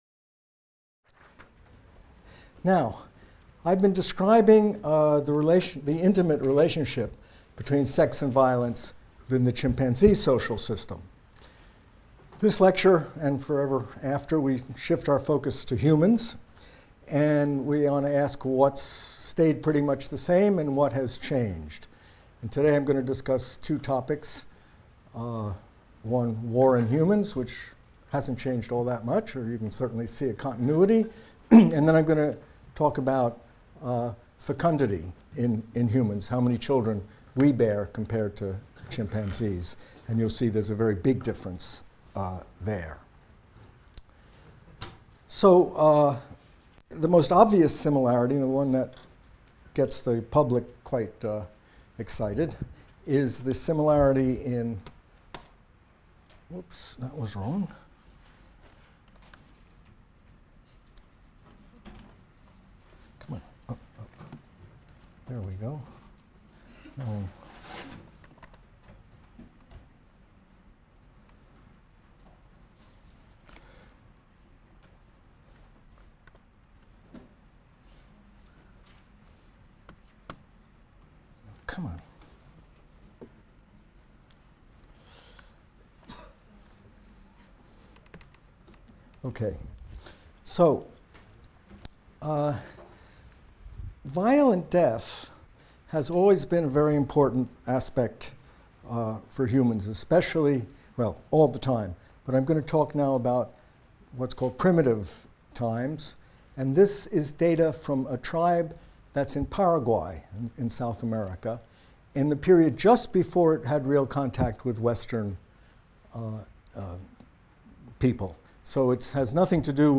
MCDB 150 - Lecture 3 - From Ape to Human | Open Yale Courses